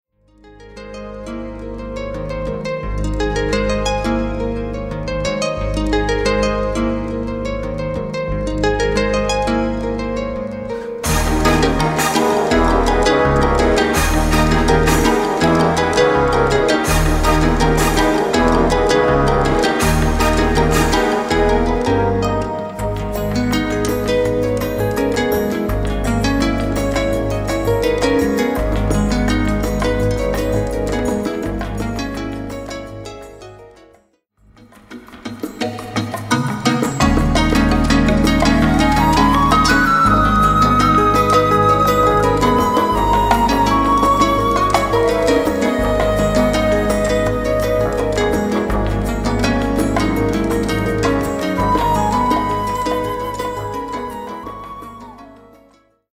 Live recordings from: